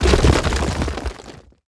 SFX monster_dead_golem.wav